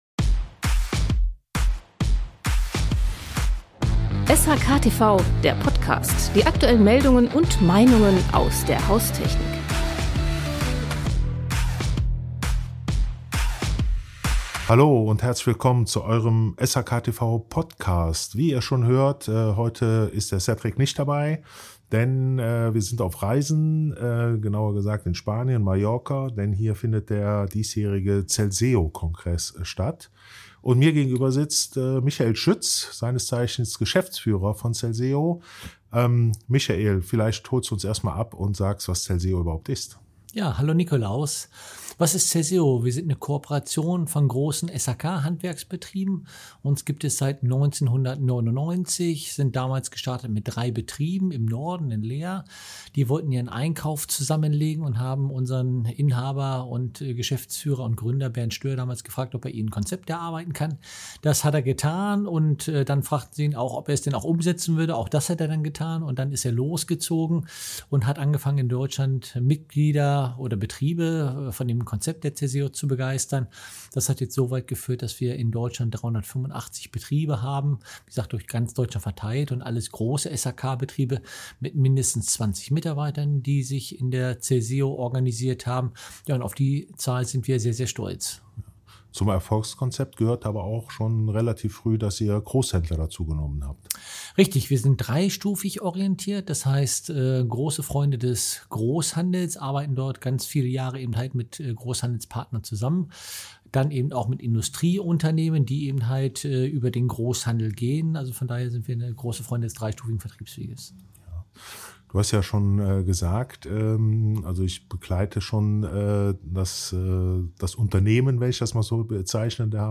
Eine besondere Form der Zusammenarbeit im Handwerk: Große SHK-Betriebe schließen sich zusammen, tauschen Wissen aus und profitieren von gemeinsamen Services – von Marketing über Einkauf bis hin zu digitalen Tools. Warum sich der Fokus längst vom reinen Einkauf hin zu Vernetzung und Digitalisierung verschoben hat, wie Betriebe Teil des Netzwerks werden und welche Rolle persönliche Empfehlungen spielen, erfahrt ihr in dieser Folge – aufgenommen auf dem SHK-Kongress auf Mallorca.